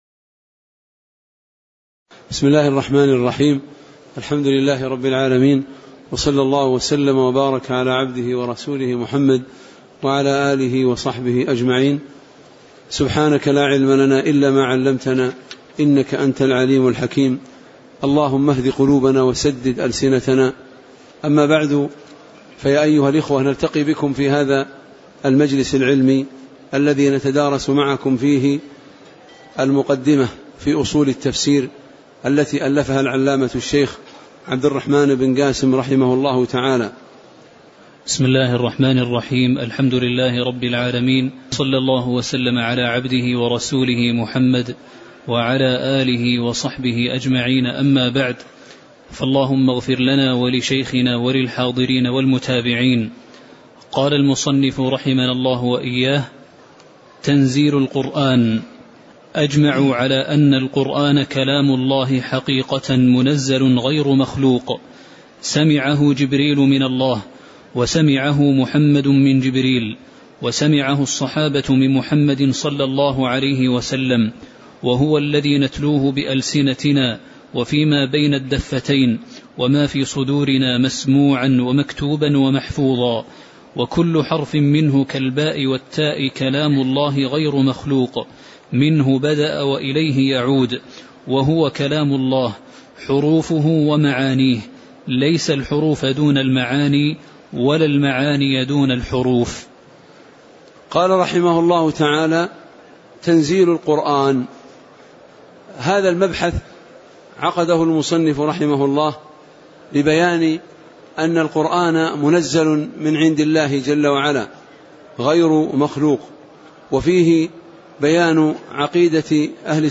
تاريخ النشر ١٠ شوال ١٤٣٩ هـ المكان: المسجد النبوي الشيخ